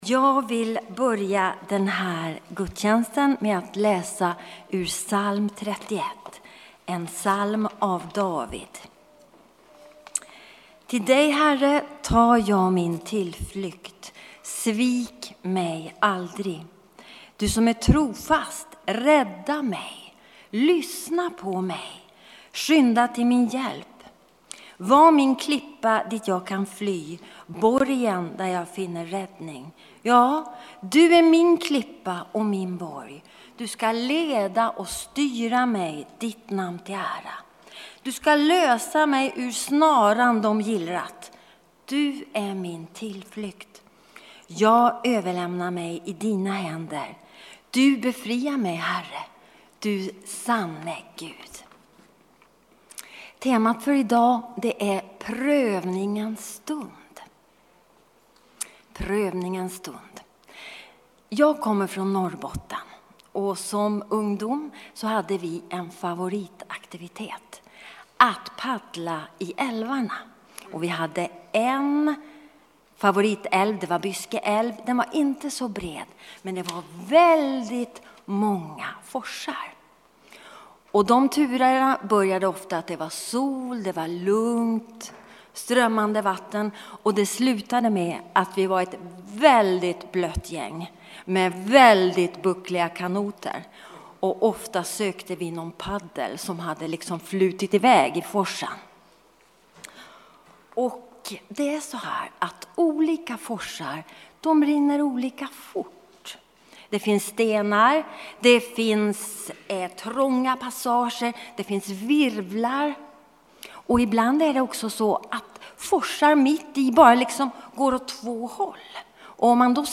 Textläsning, intro, bön och predikan Psaltaren 31:2-6, Markusevangeliet 1:12-13